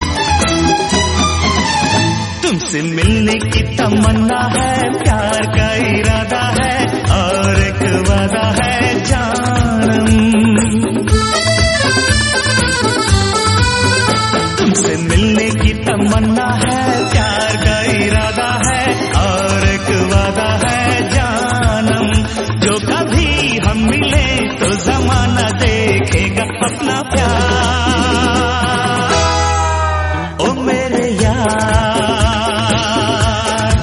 Romantic love ringtone for mobile.